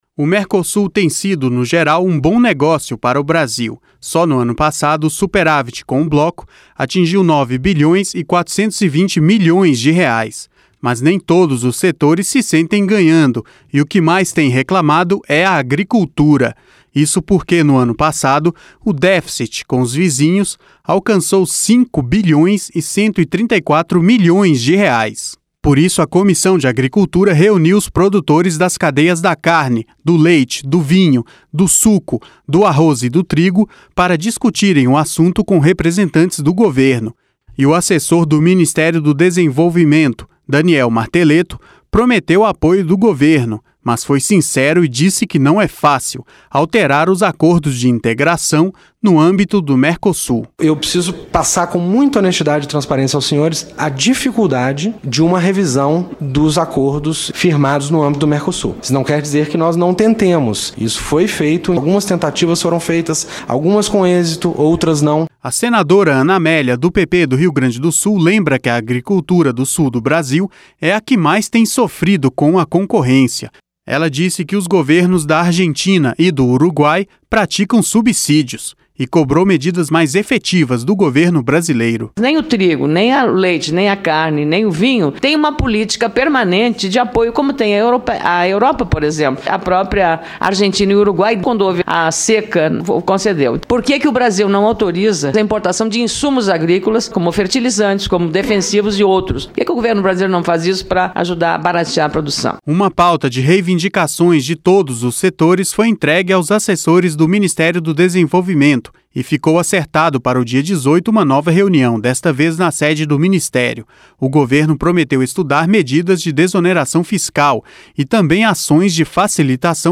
CONFIRA MAIS INFORMAÇÕES NESTA REPORTAGEM